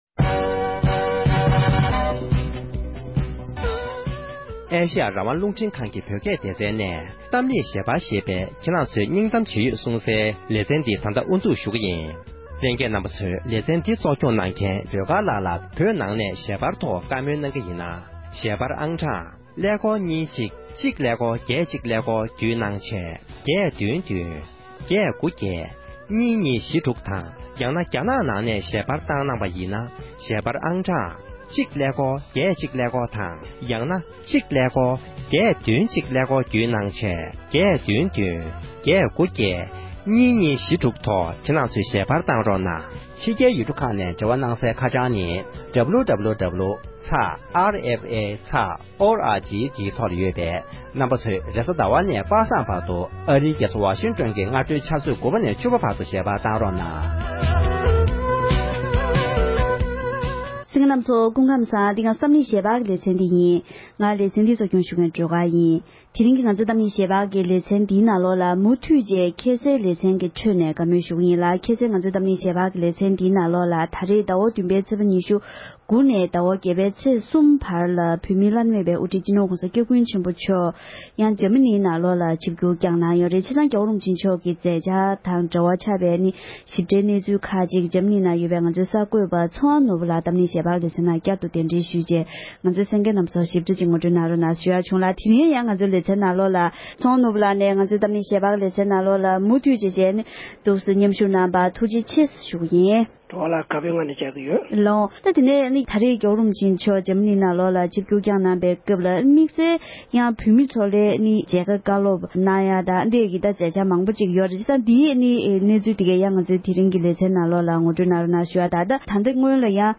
བགྲོ་གླེང